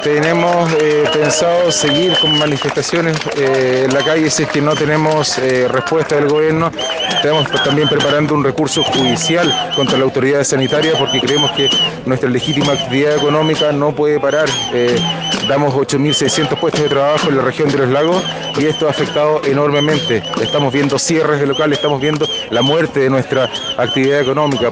En las inmediaciones de la Plaza de Armas de Osorno, se llevó a cabo una manifestación con el fin de dar a conocer el descontento que existe por la extensión de la cuarentena en la comuna y los dramas económicos que esta medida ha dejado en el comercio local.